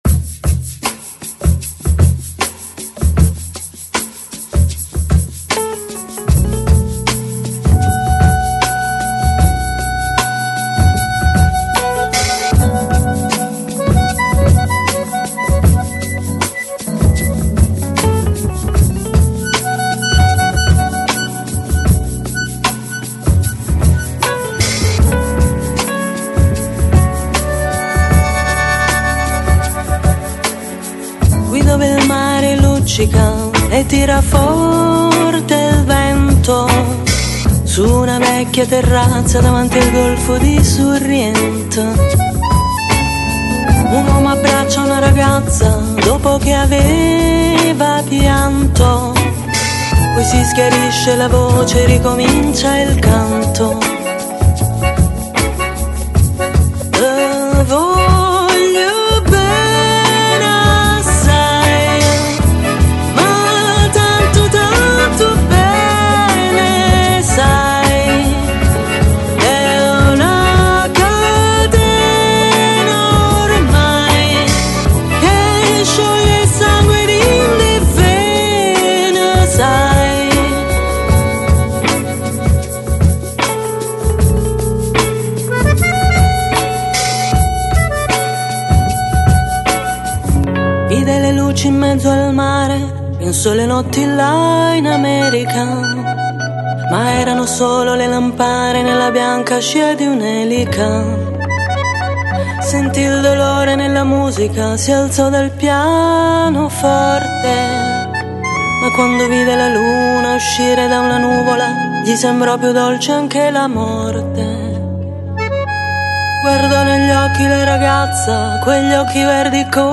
Жанр: Lounge, Chillout, Jazz, Easy Listening